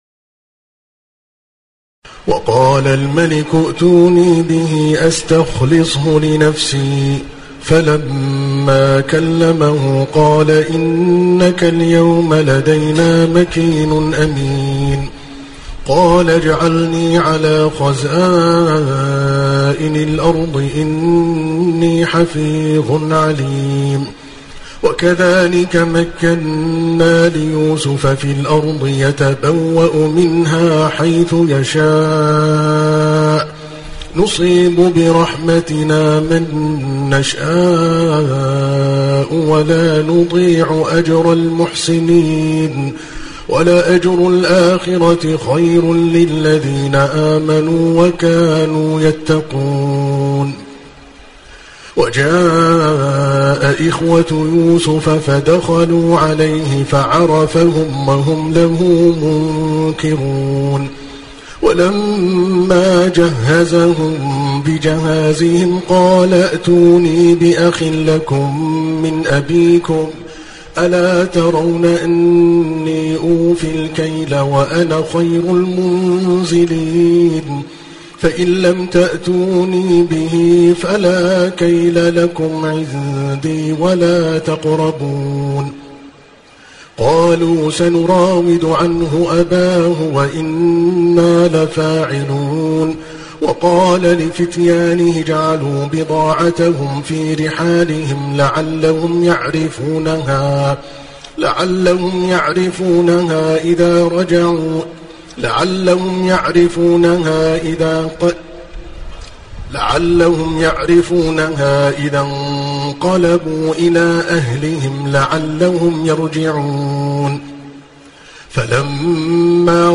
تراويح الليلة الثالثة عشر رمضان 1429هـ من سورة يوسف (54-87) Taraweeh 13 st night Ramadan 1429H from Surah Yusuf > تراويح الحرم المكي عام 1429 🕋 > التراويح - تلاوات الحرمين